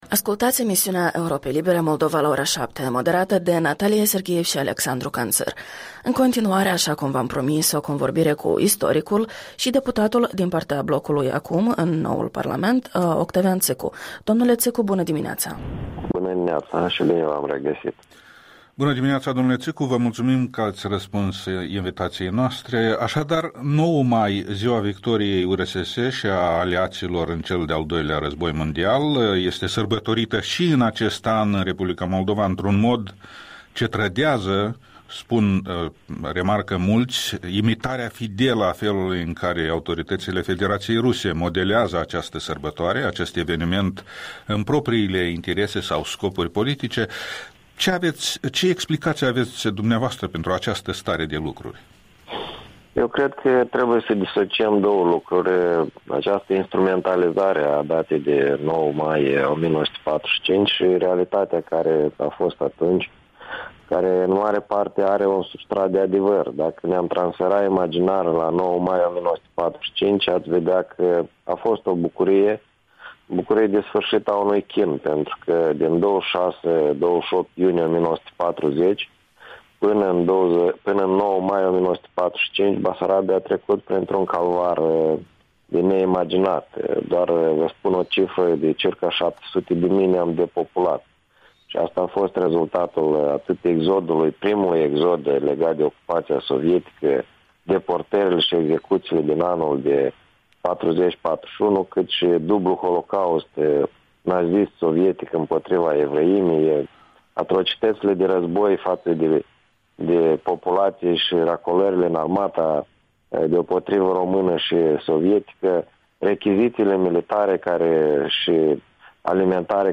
Interviul dimineții cu un cunoscut istoric și deputat de la Chișinău.
Interviul dimineții: cu deputatul Octavian Țîcu